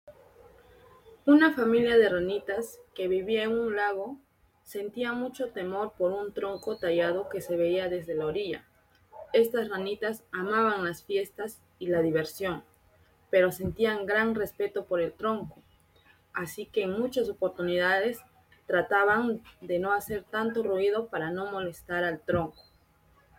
Audio libro (La ranitas y el tronco tallado)